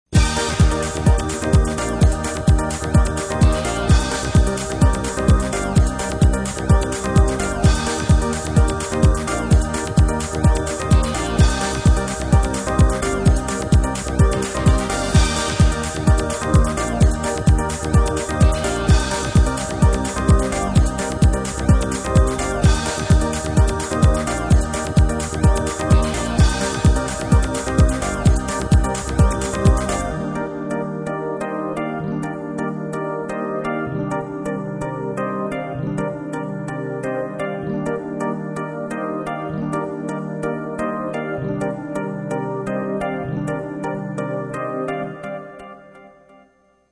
あからさまにゲームミュージックを狙った曲。なのでディストーションもあえて機械的にガギガギと。